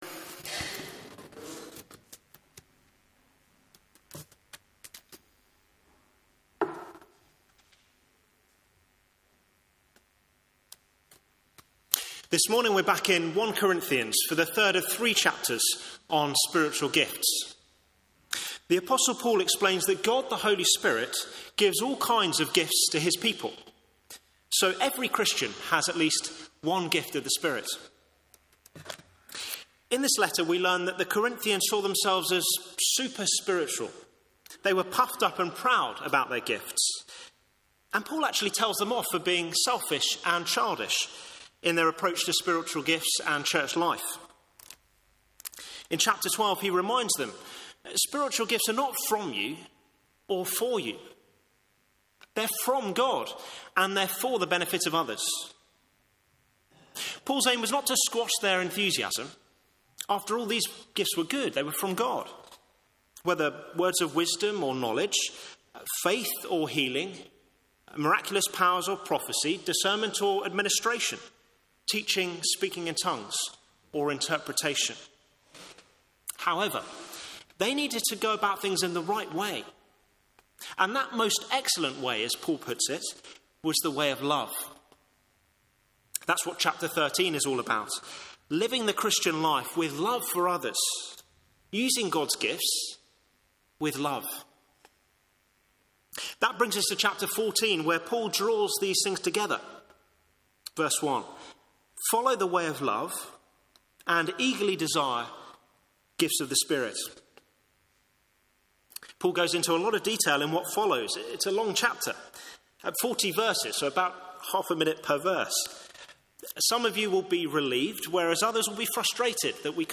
Media for Morning Service on Sun 09th Jul 2023 10:30 Speaker
Theme: Sermon In the search box please enter the sermon you are looking for.